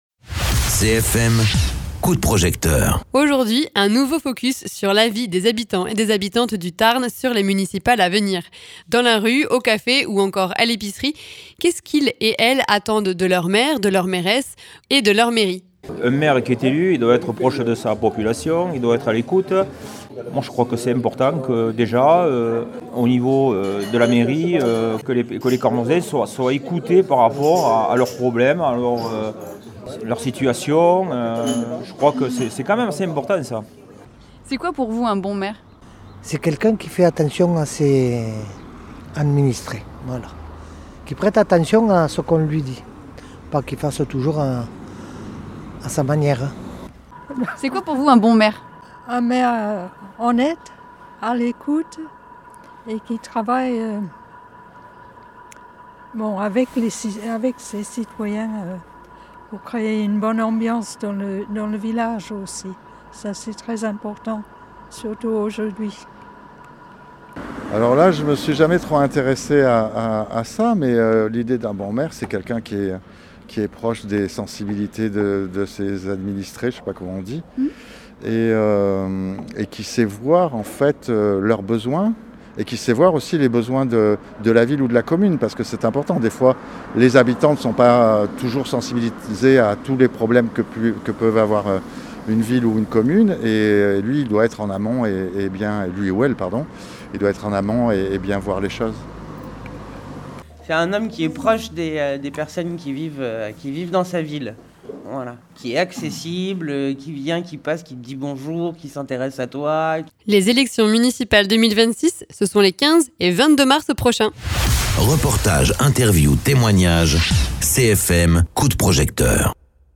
Interviews
Avec ce second micro-trottoir, on s’intéresse à ce que les habitant.e.s du Tarn attendent de leur maire, de leur mairesse et leur mairie globalement, en vue des municipales 2026, les 15 et 22 mars prochains.
Invité(s) : Habitant.e.s du carmausin, du cordais et de l’albigeois